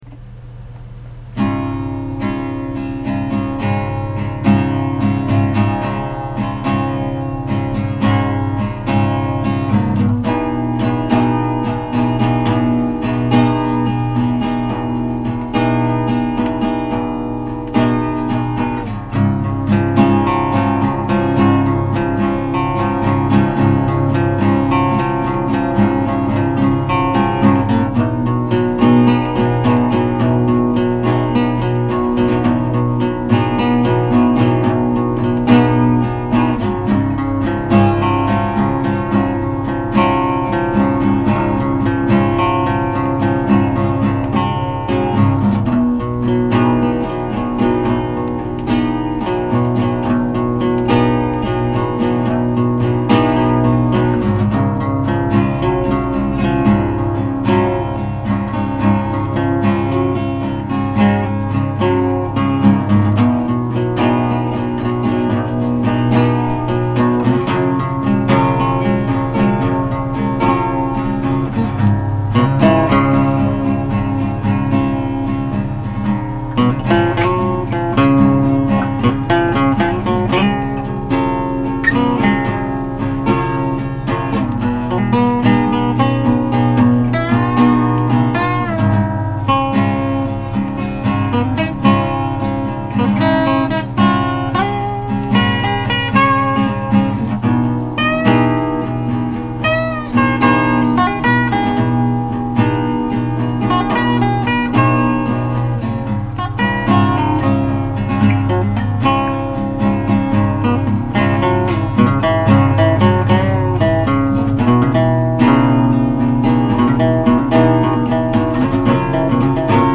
I tried to capture that feeling in this short musical piece, and it is my hope that the listener feels just as ease as I did, that one night were I was in view, of blue.